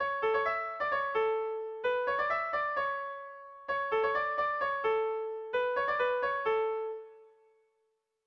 Kontakizunezkoa
Lauko txikia (hg) / Bi puntuko txikia (ip)
A1A2